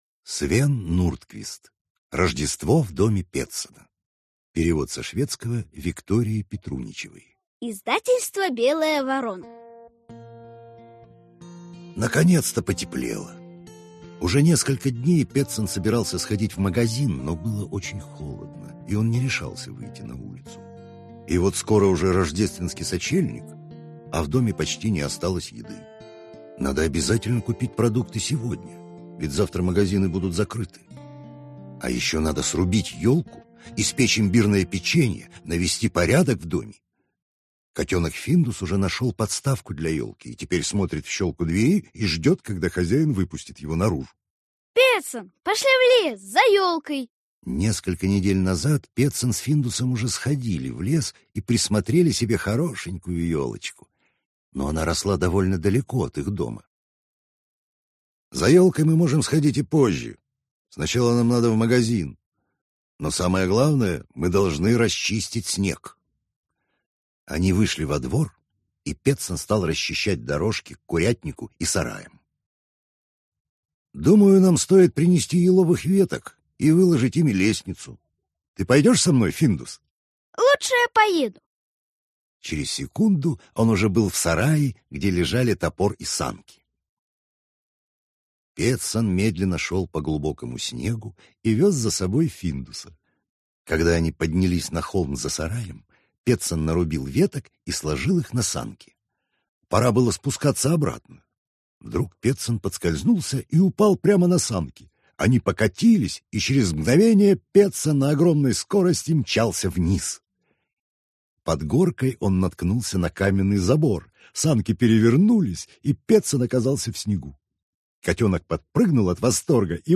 Аудиокнига Рождество в домике Петсона | Библиотека аудиокниг